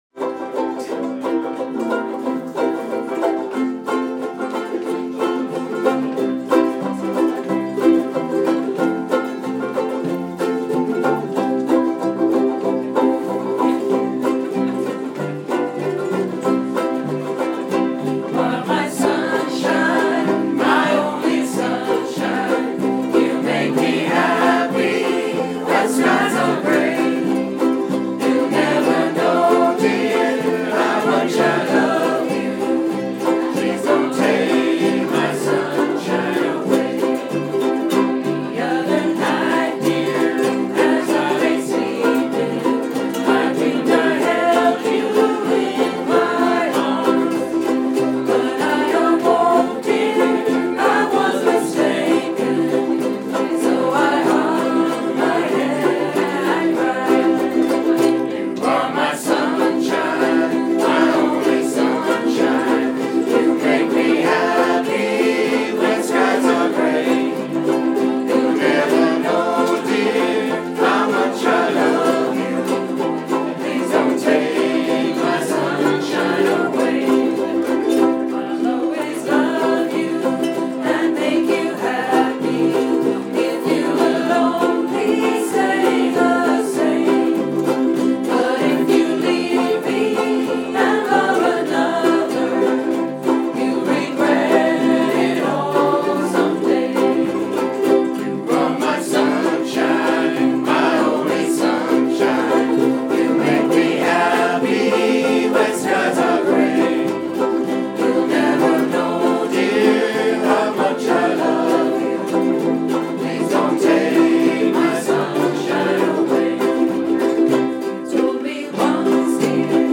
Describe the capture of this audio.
Coughlans Pub